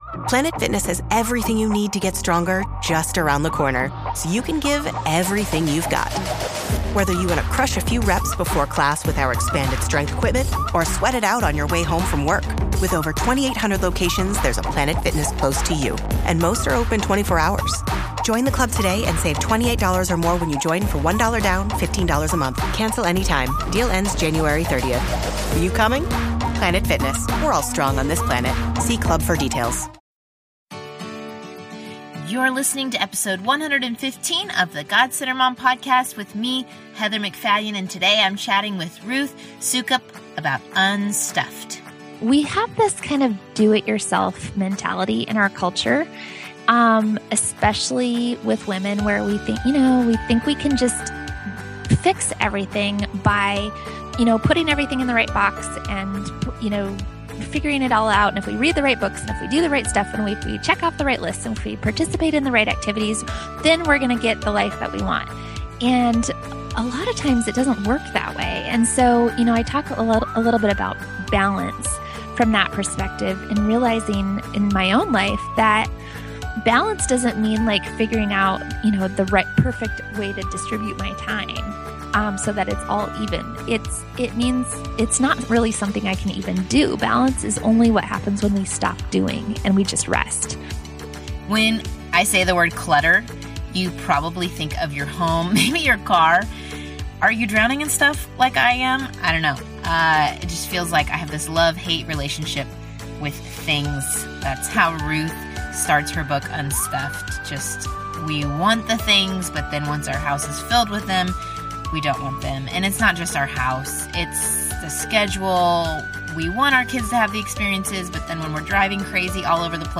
interviews guests discussing the topic of staying God-centered...both replacing "me" with "He" and remembering we are centered in Him.